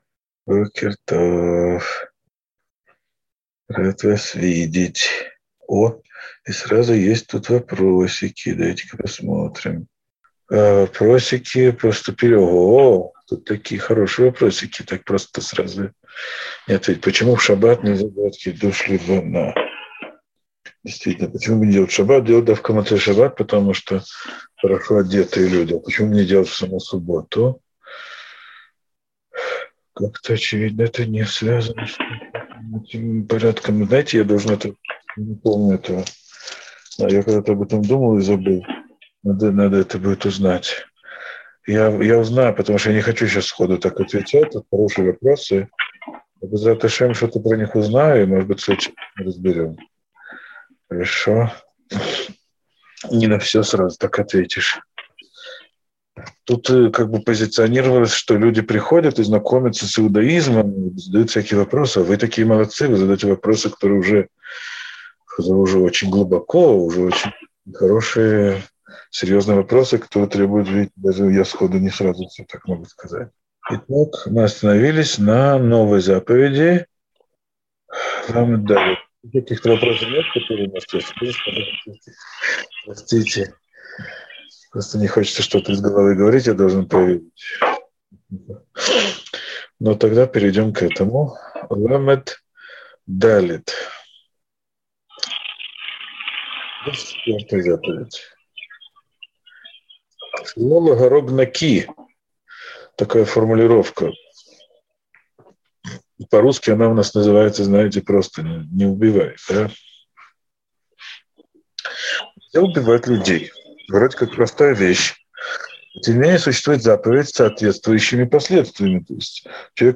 Урок 48. Почему не благословляют на луну в шаббат